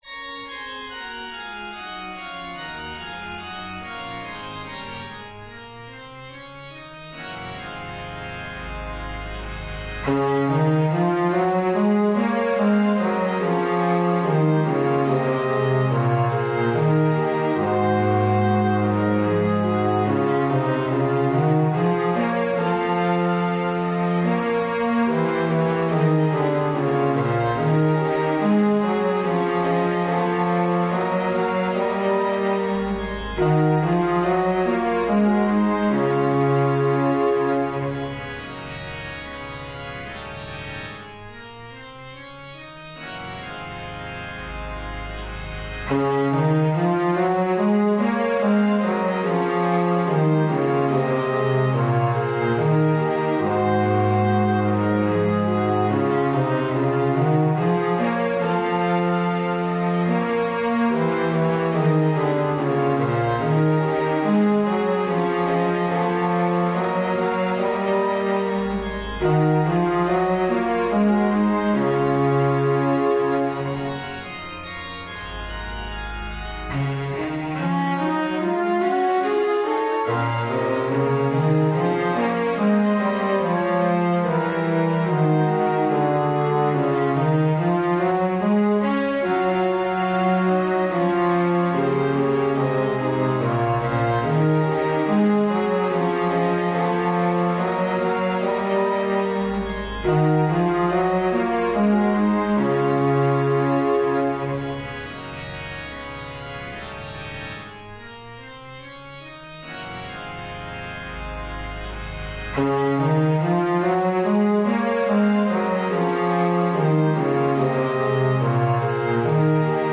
FullScore